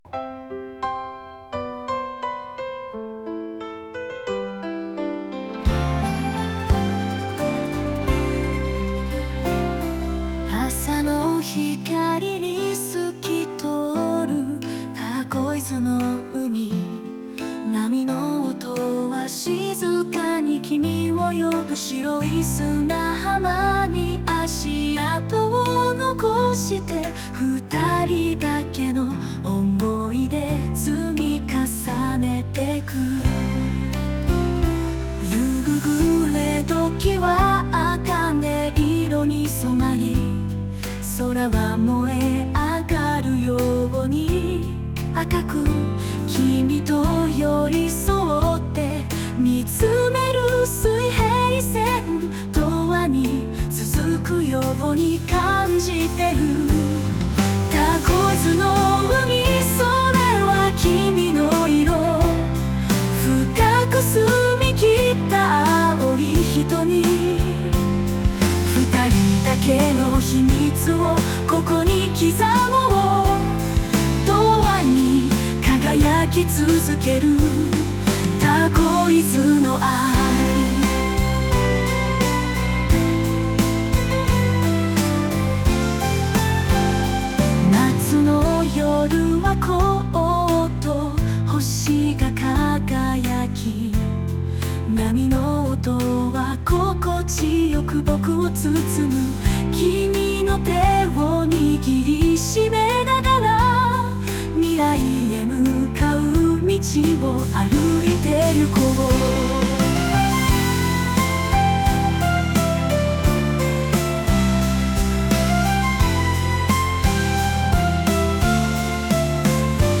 歌詞ありフリー音源。